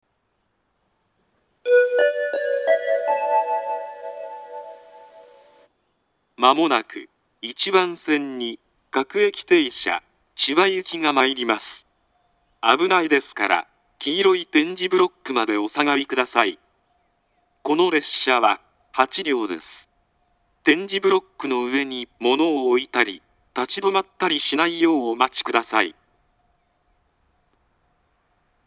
１番線接近放送
自動放送は合成音声です。
接近放送は詳細ですが、戸閉め放送は全列車簡易放送です。